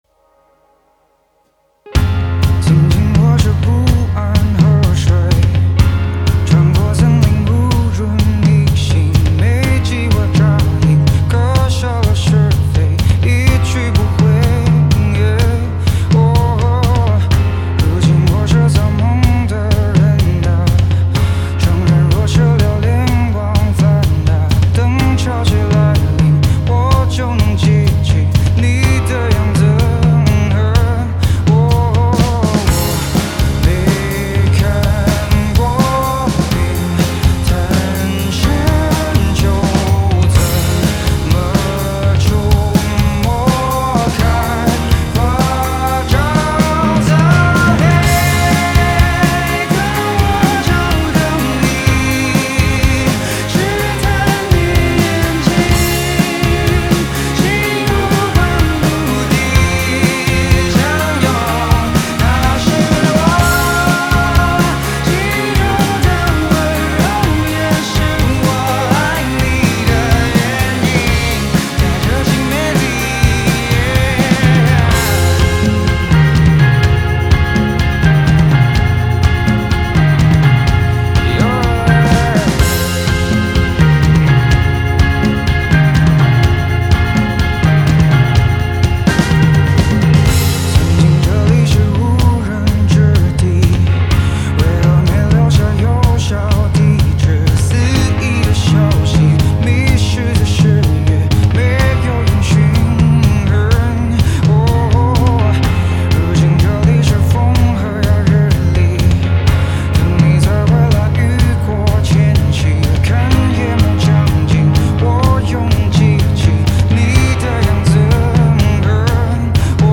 架子鼓